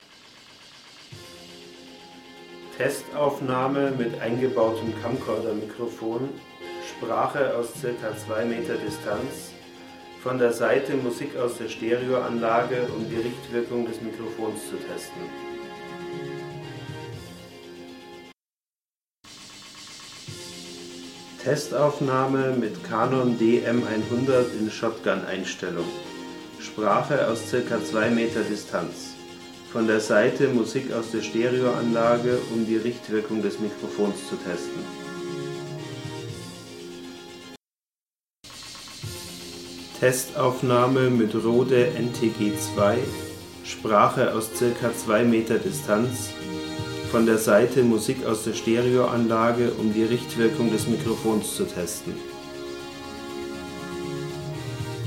Habe schnell einen kleinen Vergleich aufgenommen, und zwar eine Sprachaufnahme aus ca. 2 Meter Distanz.
1) Eingebautes Camcordermikro des Canon HF-100 (dürfte dem HF-11 entsprechen)
2) Aufsteckmikrofon Canon DM-100 (Shotgun-Stufe)
3) Richtmikrofon Rode NTG-2 mittels XLR-Klinke-Kabel
Die drei Aufnahmen kommen hintereinander. Sind mit Aussteuerungsautomatik aufgenommen und nicht bearbeitet, einfach nur fürs Web in mp3 konvertiert:
richtmikros.mp3